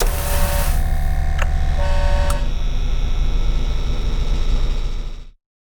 shieldson.ogg